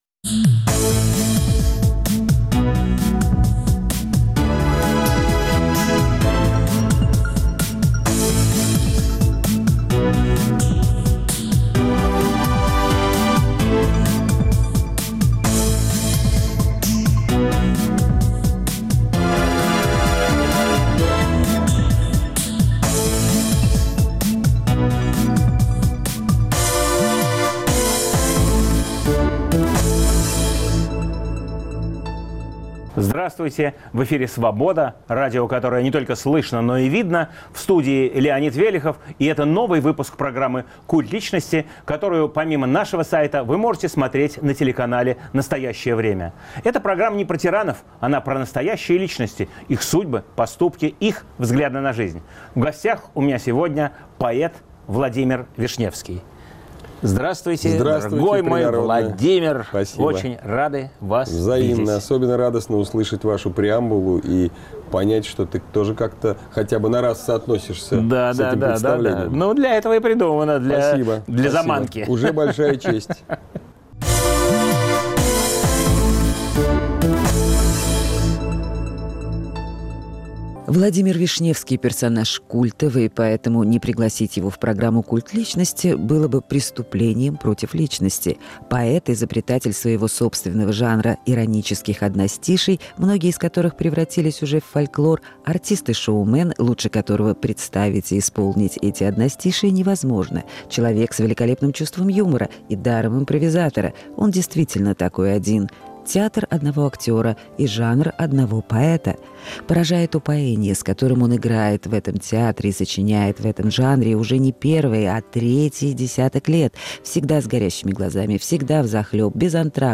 Новый выпуск программы о настоящих личностях, их судьбах, поступках и взглядах на жизнь. В студии поэт Владимир Вишневский.